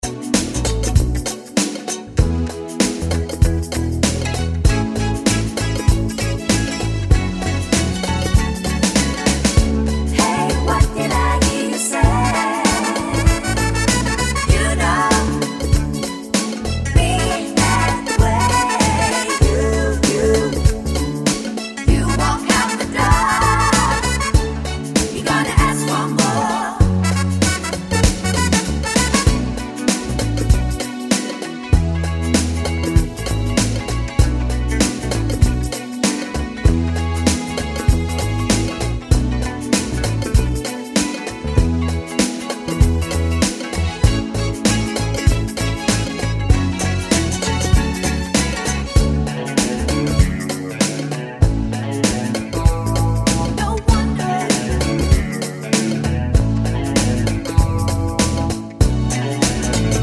Minus Sax But With Backing Vocals Pop (1980s) 4:04 Buy £1.50